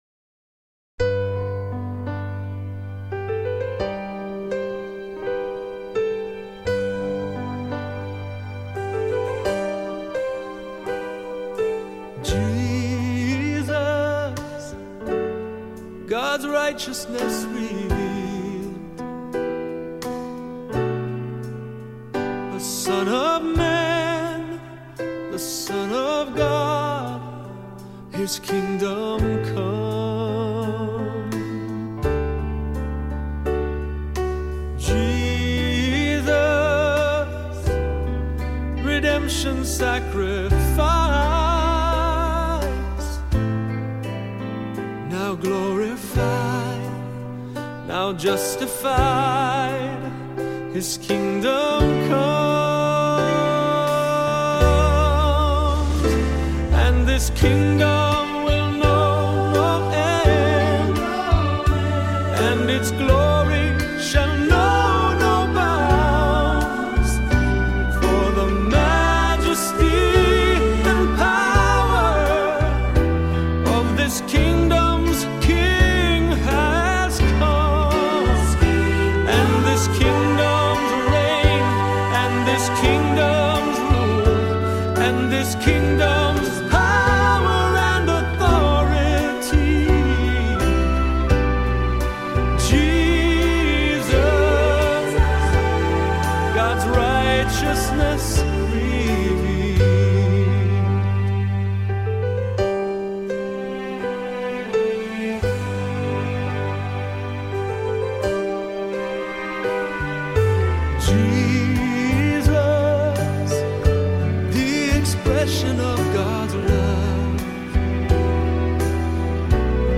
Here You Can Find Some Peaceful And Soothing Worship Songs
Worship Songs